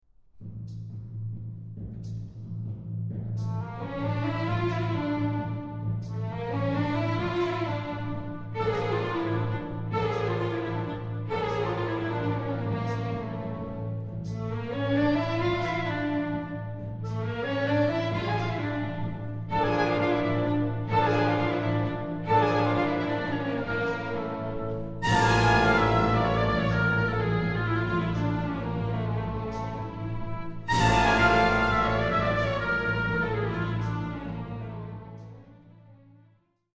Ballet Music